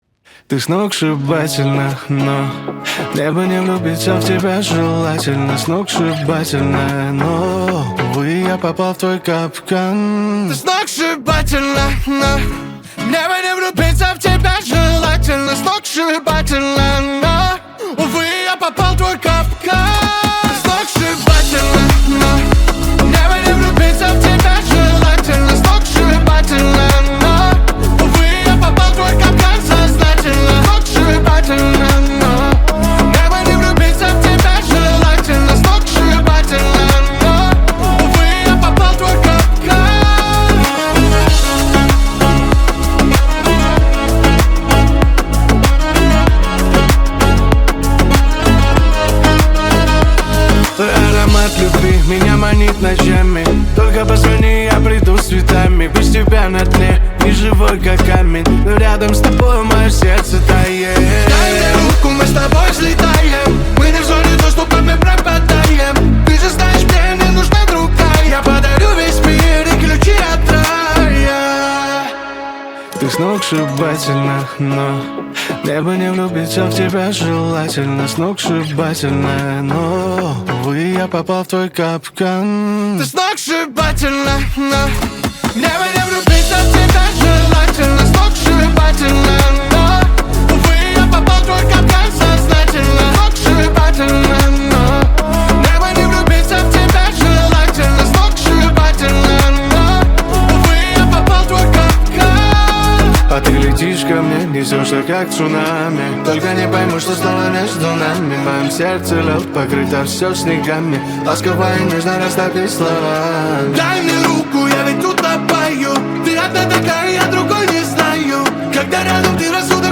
Жанр: Узбекские треки